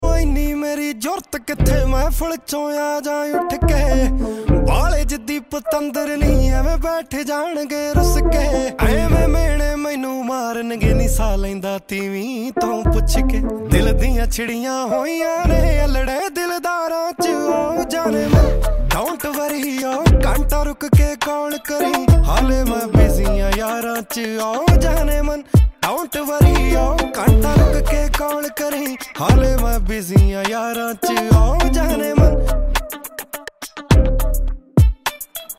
latest Punjabi Songs